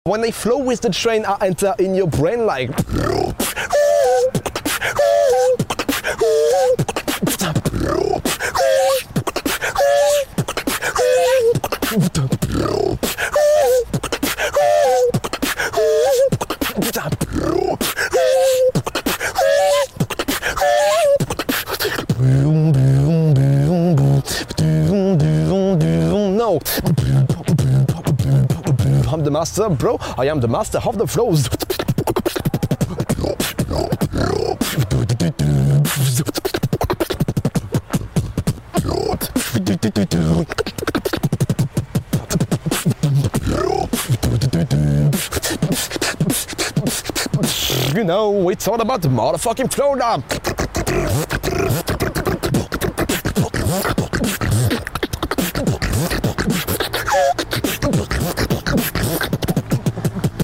BEATBOXER